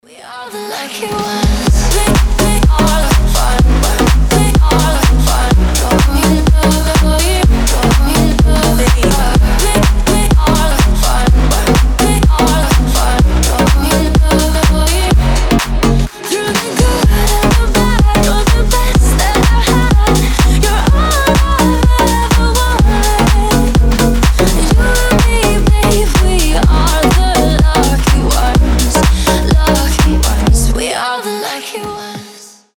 • Качество: 320, Stereo
громкие
женский вокал
Electronic
EDM
future house
энергичные